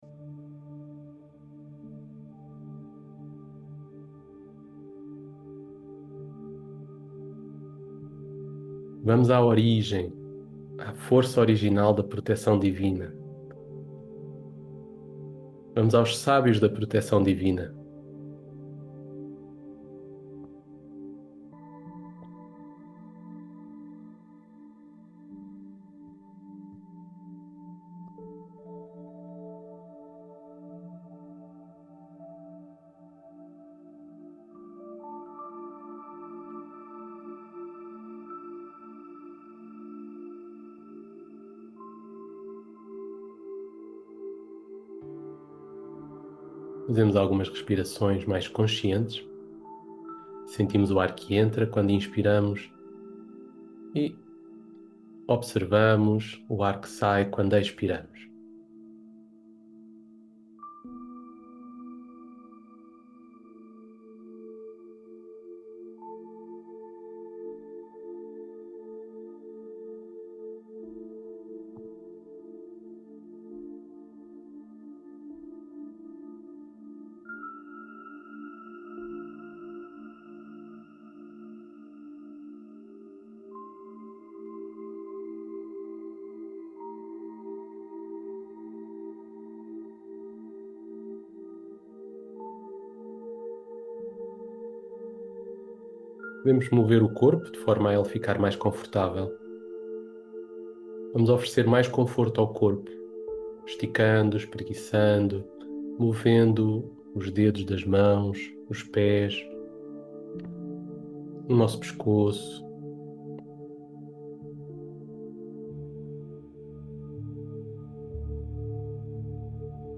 Meditação-Deus-Está-Aqui-Aula-4.mp3